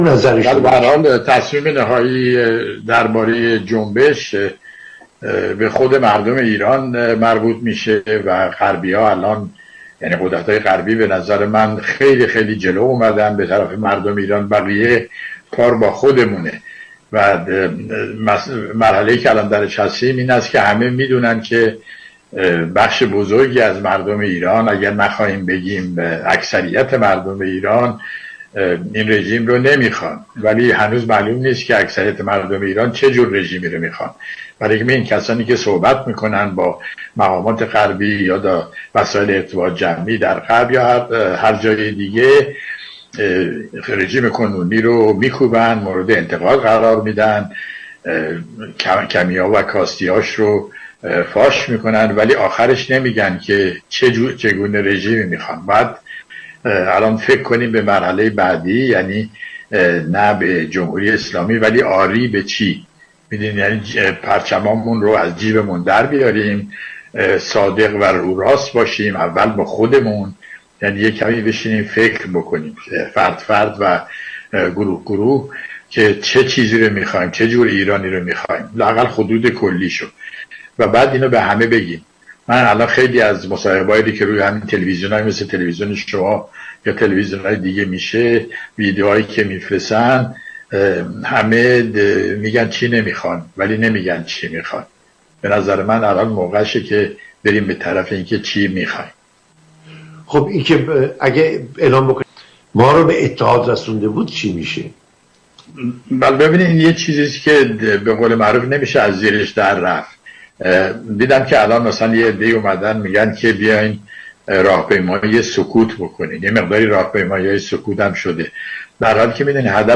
نگاهی به آینده خیزش و روشنگری های عمیقتر دیگر به ویژه در حیطه روابط حکومت خمینیست وامانده و چین را در این آخرین پادکست از گلچین بیانات استاد امیر طاهری بشنوید.